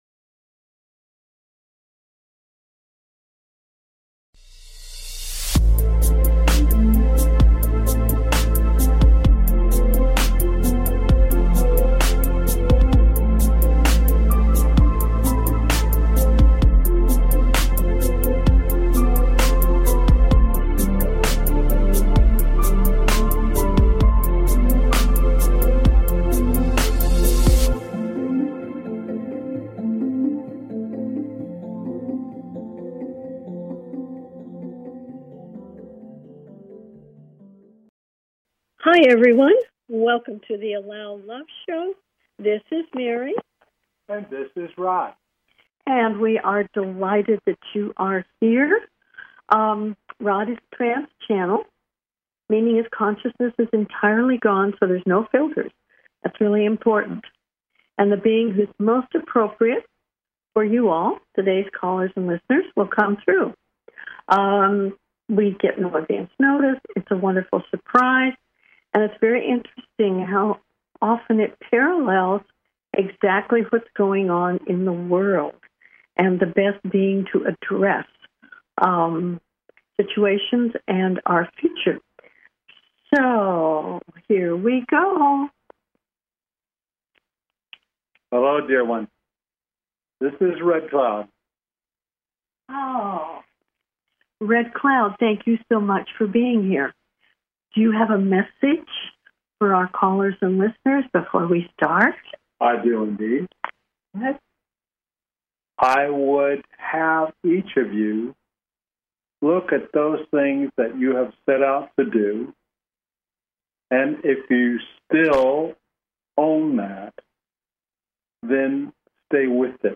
Talk Show Episode, Audio Podcast
Their purpose is to provide answers to callers’ questions and to facilitate advice as callers request.